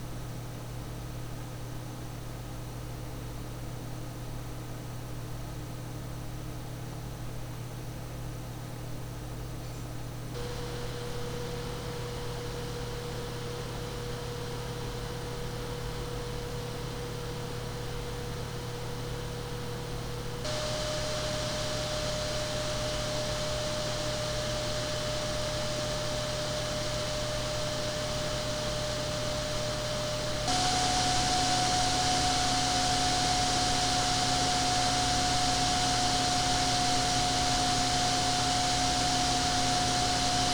Se fa questo rumore, la ventola funziona bene: